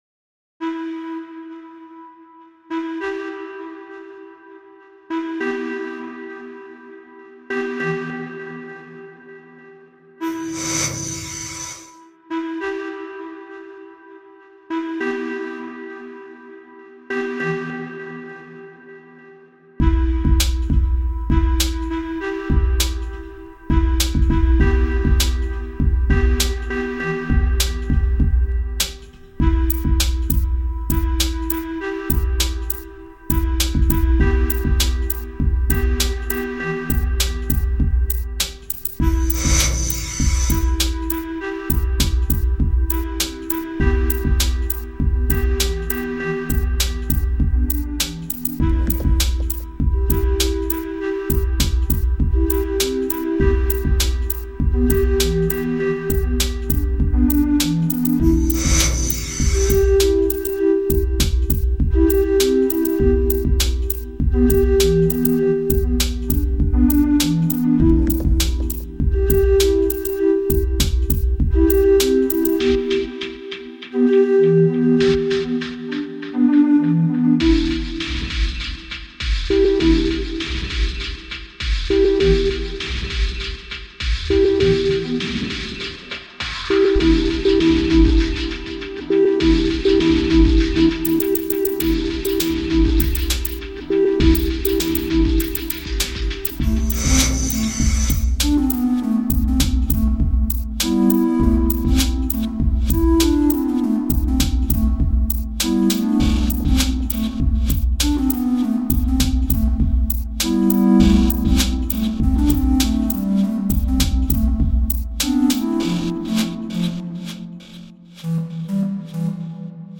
4. Genre: Downtempo